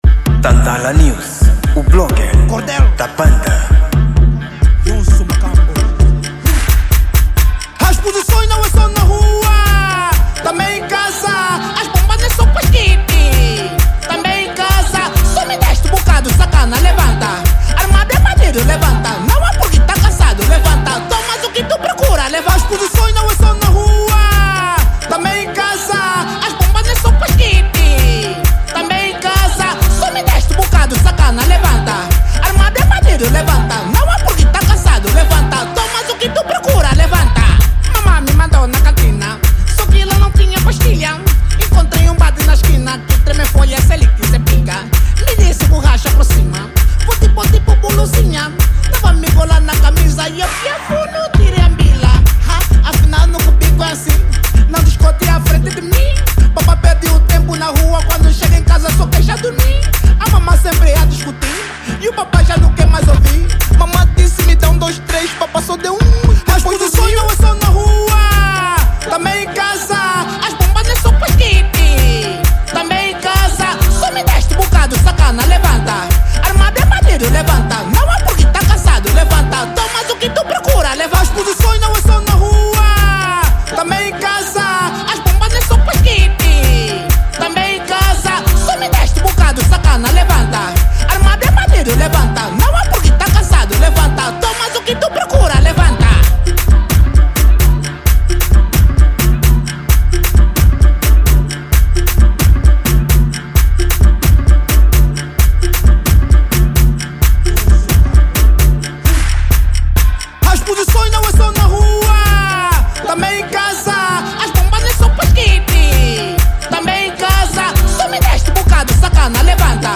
Gênero: Afro House